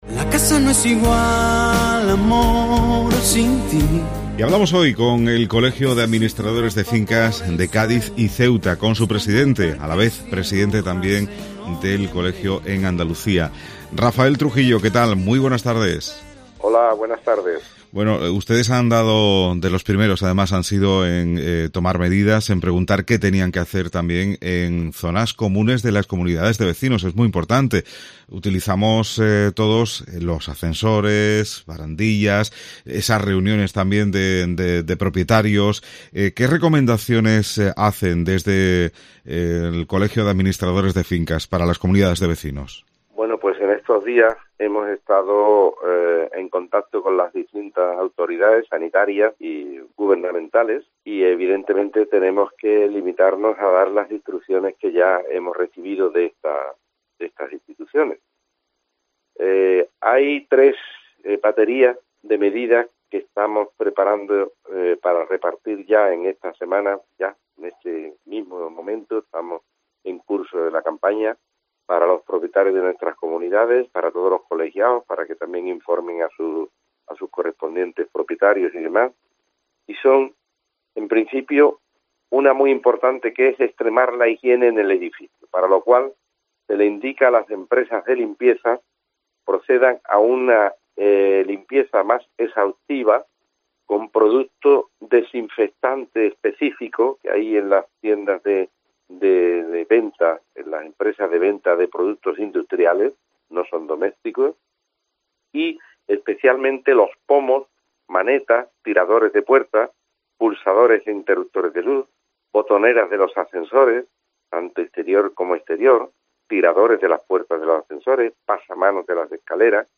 administrador de fincas